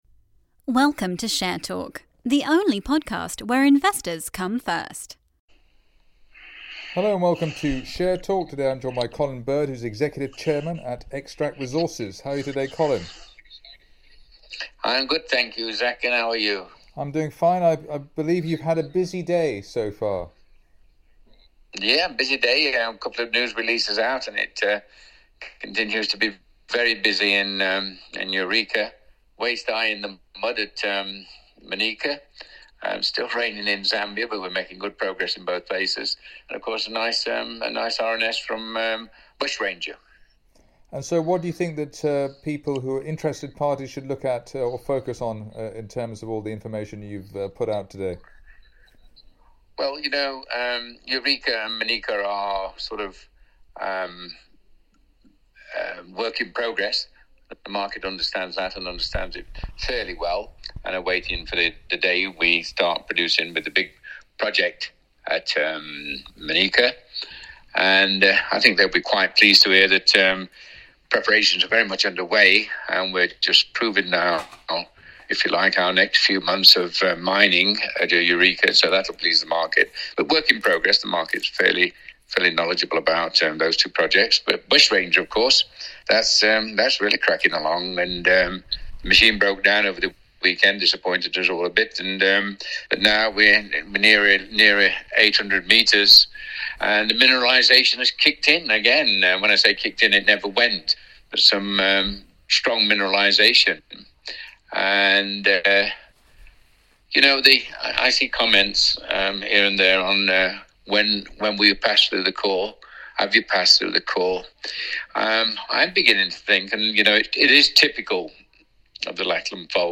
Xtract Resources plc (XTR.L) Eureka Copper Project Drilling Update/Interview